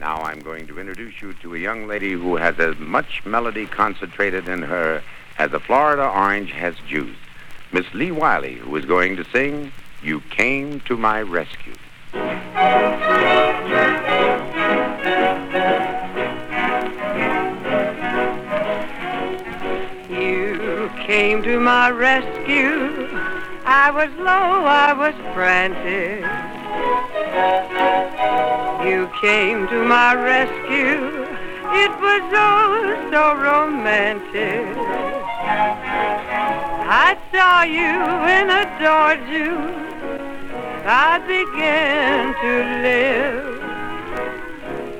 音源は1930年代の物のみです。
Jazz, Pop, Vocal　USA　12inchレコード　33rpm　Mono
ジャケ汚れ　盤良好　元音源に起因するノイズ有